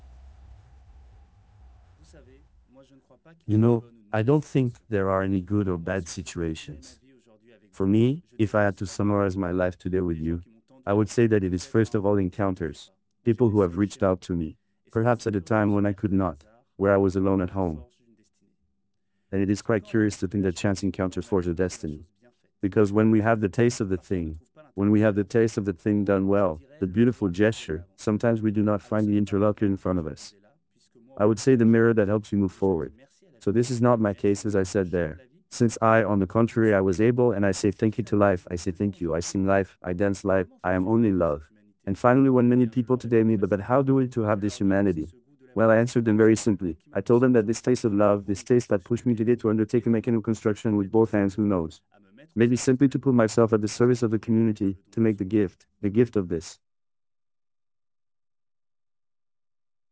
audio-to-audio speech-translation video-to-audio voice-preserving
Hibiki: High-Fidelity Simultaneous Speech-To-Speech Translation